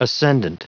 Prononciation du mot ascendant en anglais (fichier audio)
Prononciation du mot : ascendant